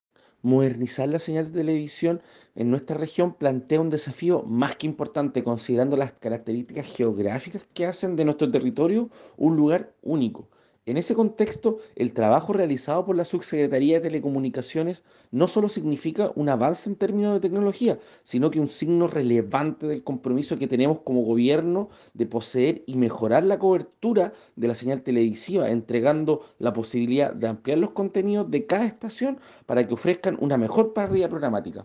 El Seremi de Transportes y Telecomunicaciones en la región, Jean Ugarte se refirió a esta innovación: